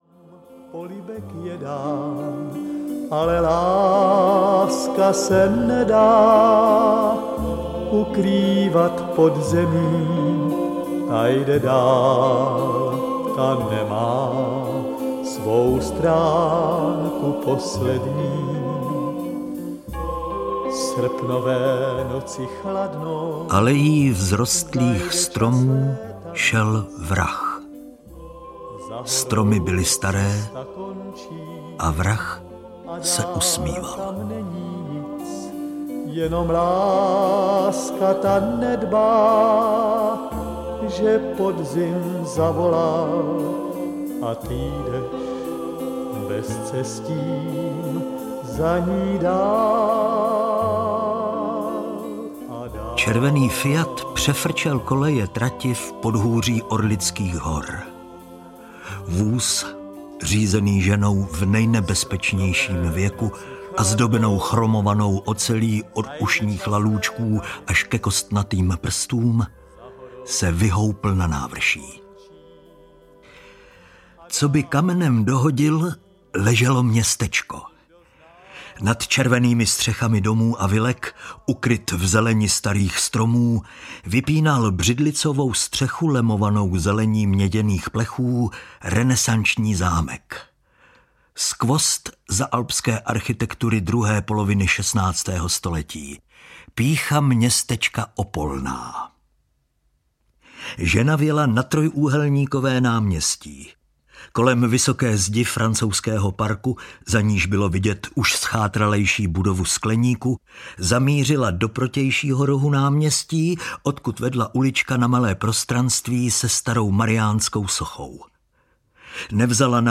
Smrt talentovaného ševce audiokniha
Ukázka z knihy